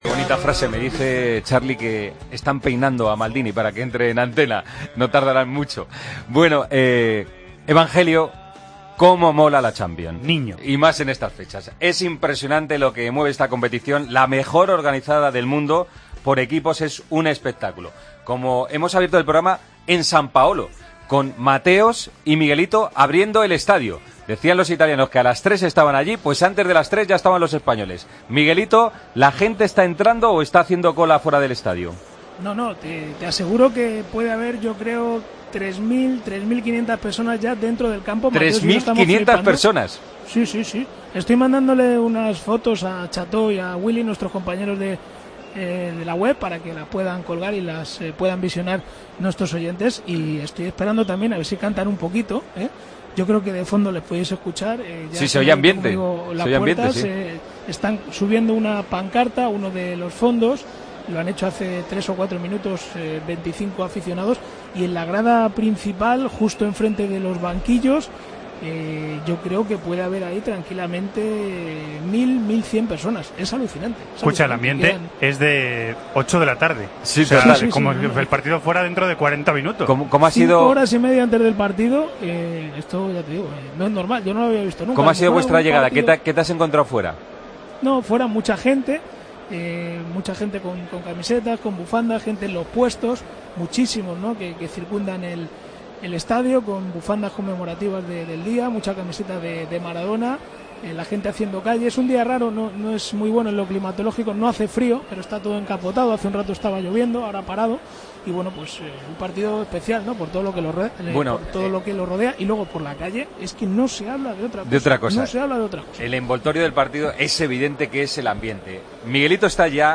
Increíble ambiente en San Paolo, a 5 horas del comienzo del partido